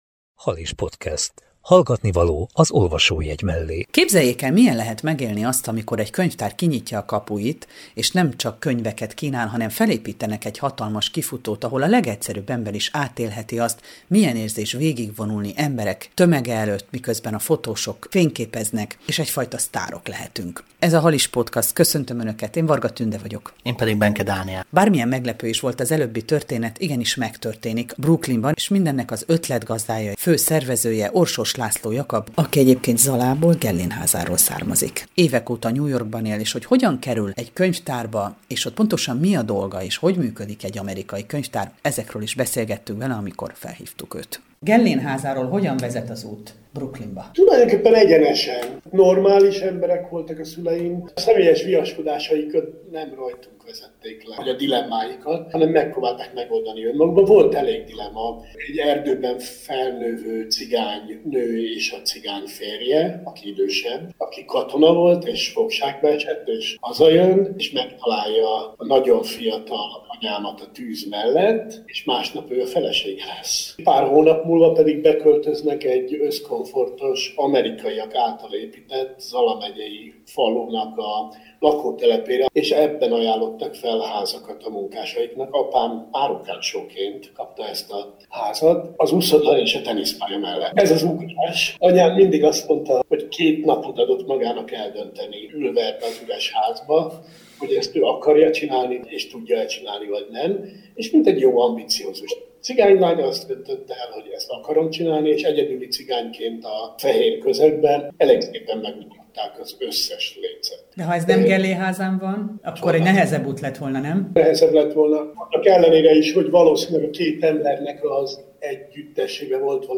Halis Podcast 65 - Amerika és a közkönyvtárak - beszélgetés